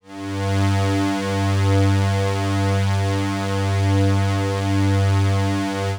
G2_trance_pad_2.wav